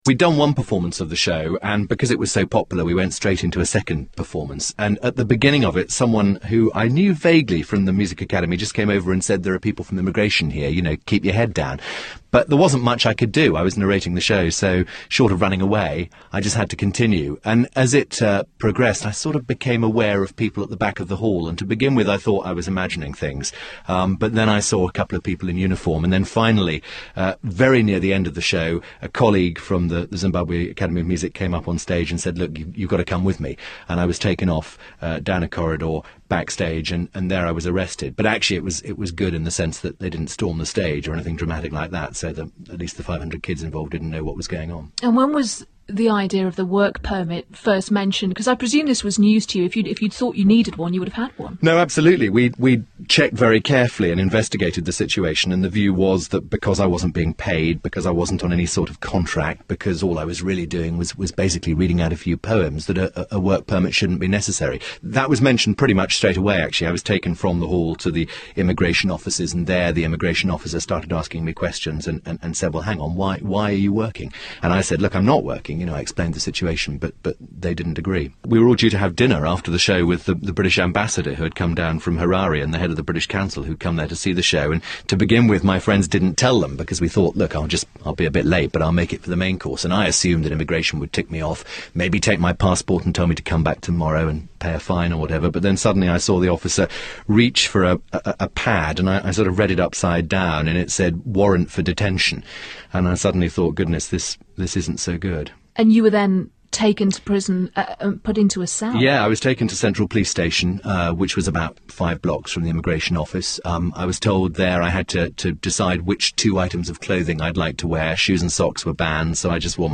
A few hours after detention, he injured himself, which resulted in his transfer to hospital. Altogether, he was in custody for six days; although as this interview on Five live confirms, he was well-treated and thus stoic about the whole incident.